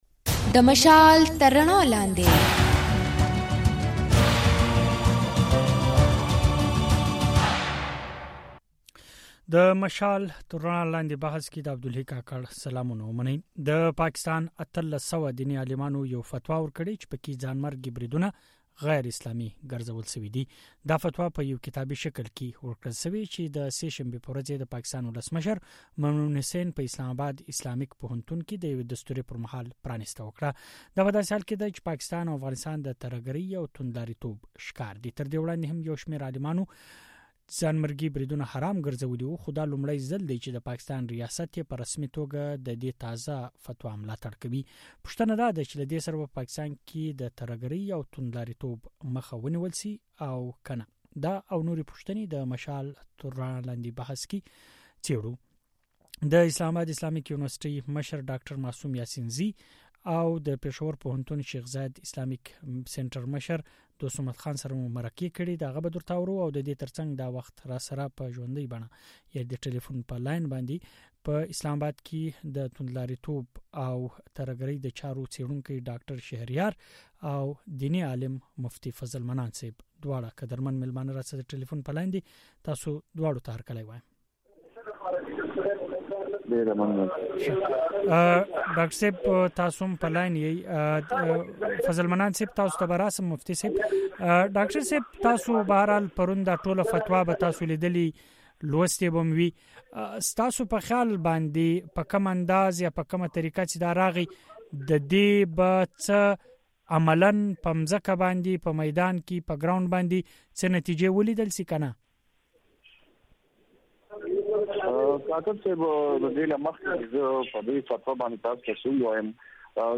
دا د مشال راډیو د ځانګړي بحث او شننو اوونیزې خپرونې پاڼه ده.